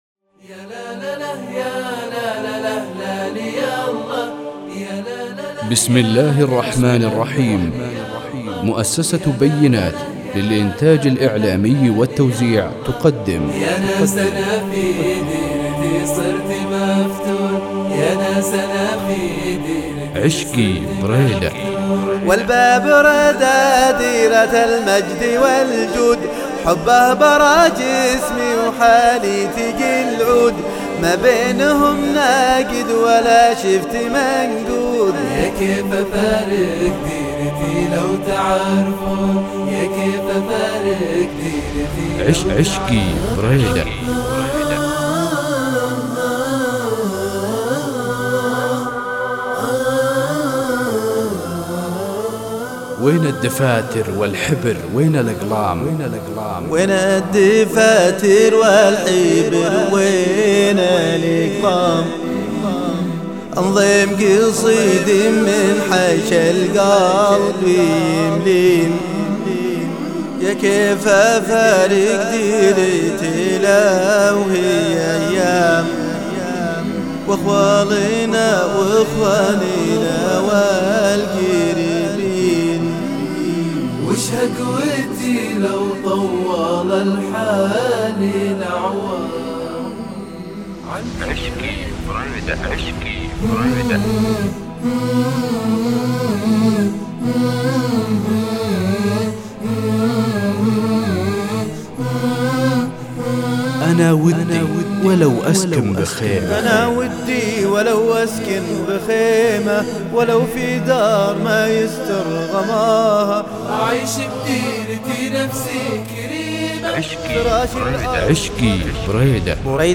الألحان والكورال أكثر من رائع ..